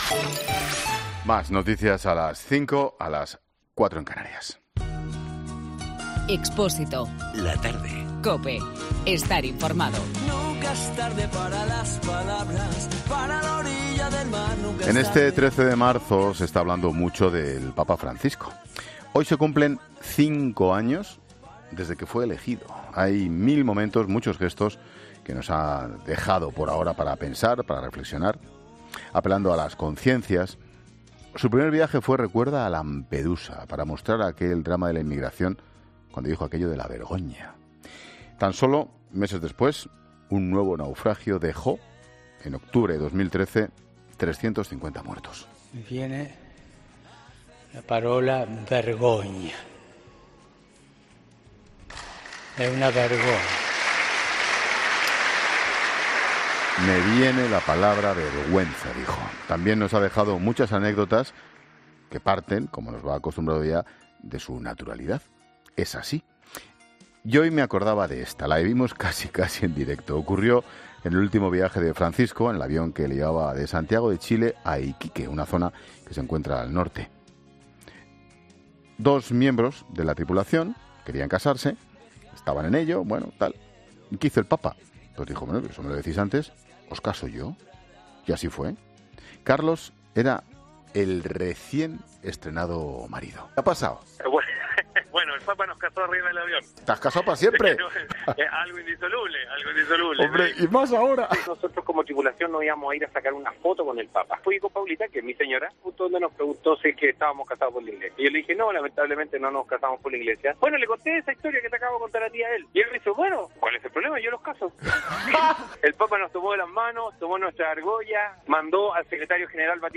Ángel Expósito entrevista a Víctor Manuel Ochoa, un gran conocedor del Papa Francisco